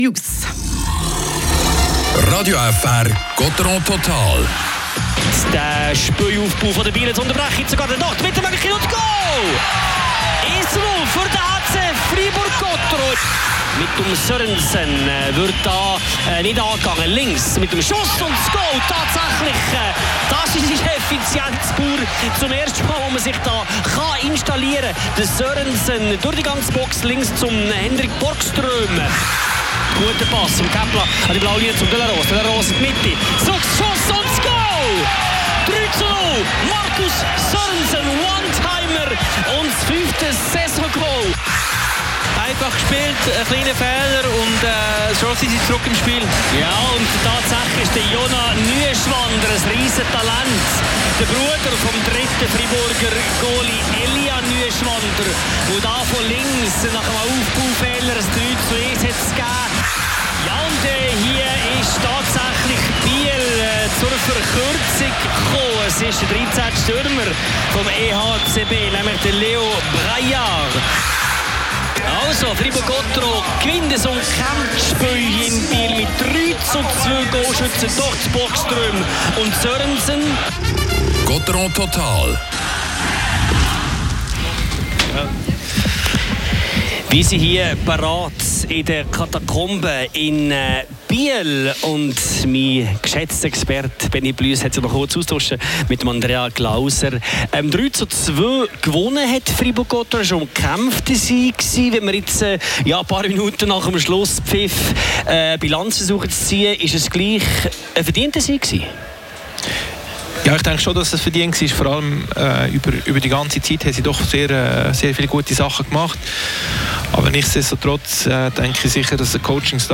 Nach der gestoppten Siegesserie meldet sich Gottéron eindrucksvoll zurück: Ein 3:2-Sieg in Biel bringt wichtige Punkte und Schwung vor dem Duell gegen den SC Bern. Spielanalyse
Interviews mit Lars Leuenberger und Julien Sprunger.